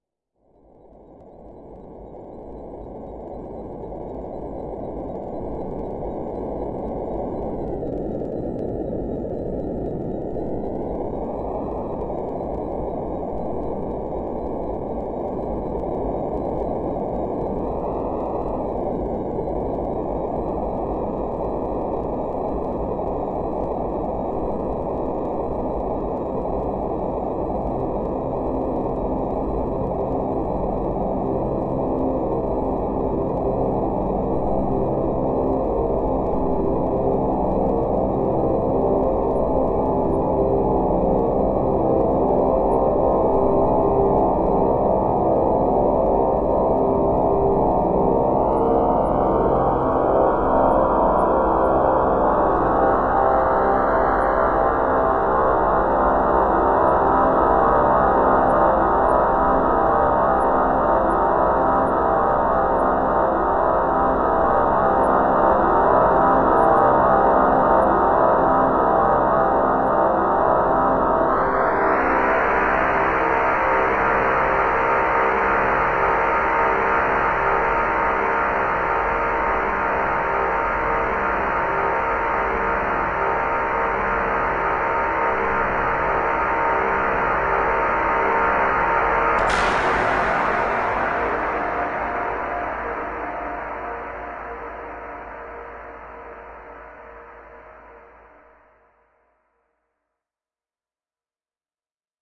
合成器的声音 " ph 1.2 o2b b10 99 噪音
描述：一种声音，开始时振荡器设置为噪音，给人一种风的感觉，然后随着共振的加入，变得更有金属感和音乐感。
Tag: 环境 气氛 电子 音乐 噪声 加工 科幻 合成器